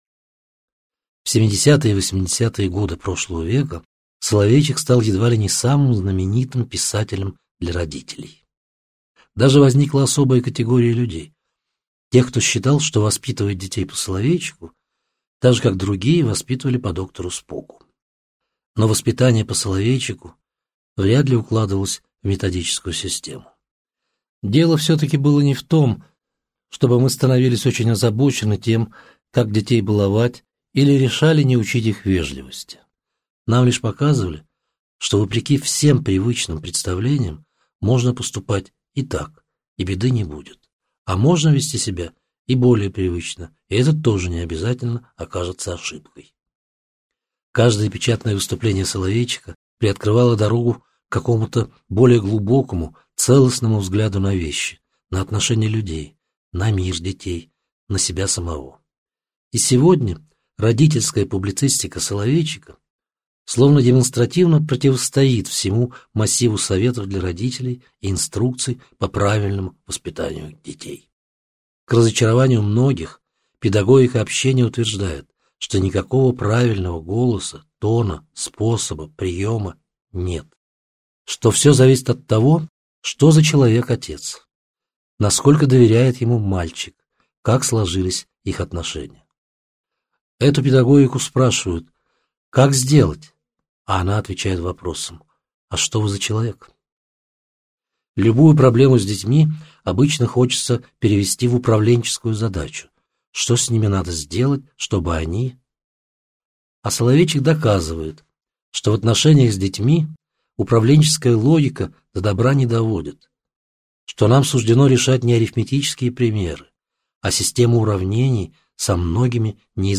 Аудиокнига Непрописные истины воспитания. Избранные статьи | Библиотека аудиокниг